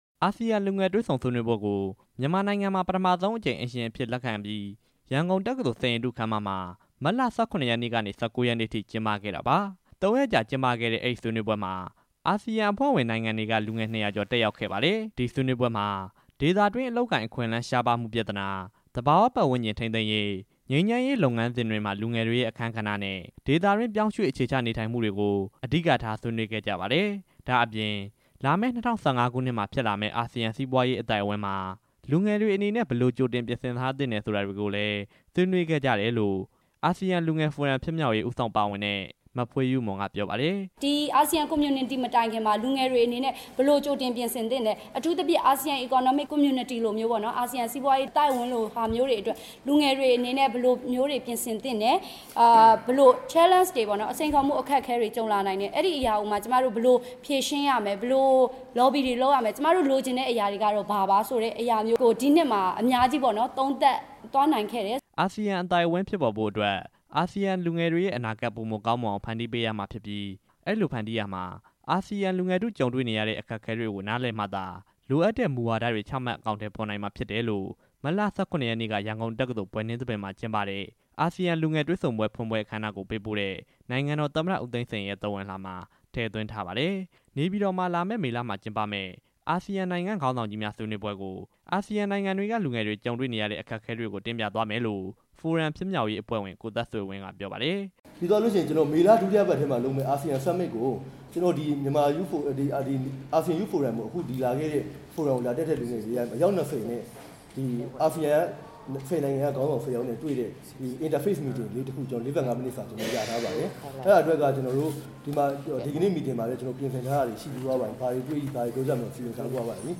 အာဆီယံ လူငယ်ဖိုရမ်အကြောင်း တင်ပြချက်